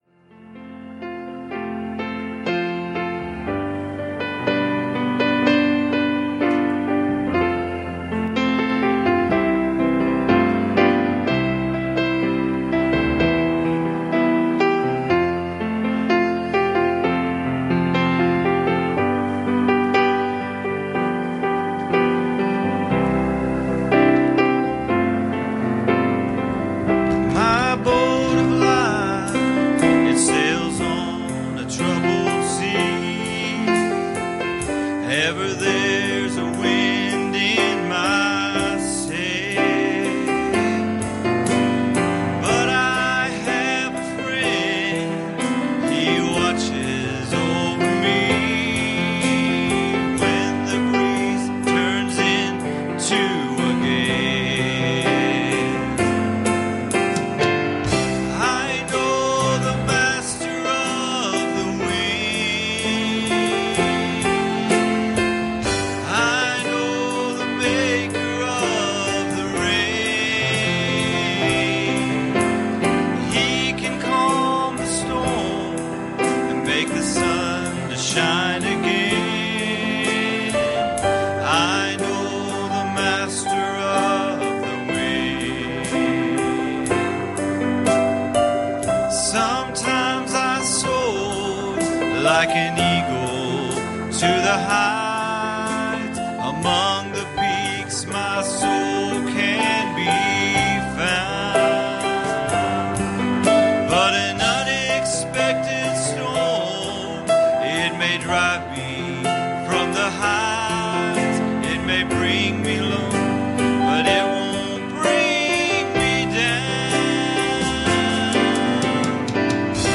Passage: 1 Corinthians 1:12 Service Type: Wednesday Evening